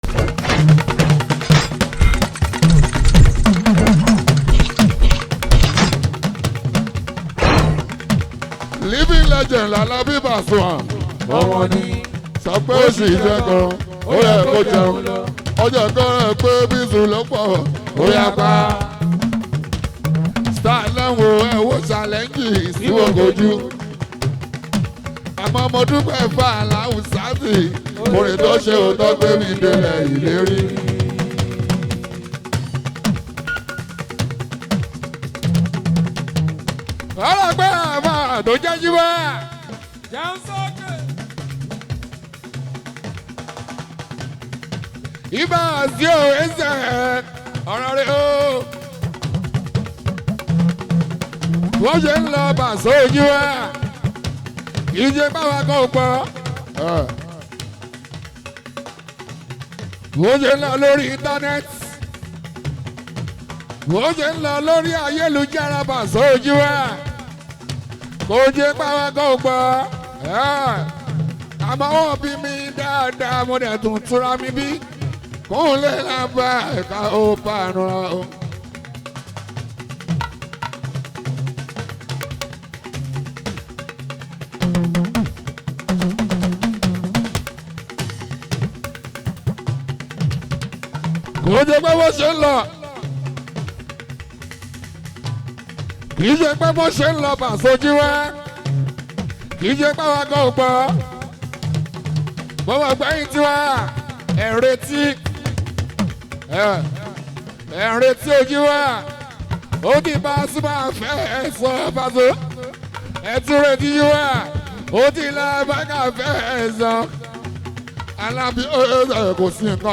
blends Afrobeat with contemporary sounds